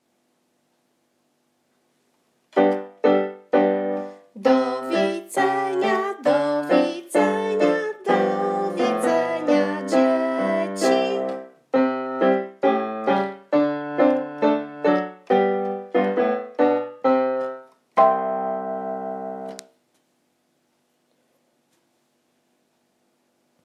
Na koniec muzyczne pożegnanie.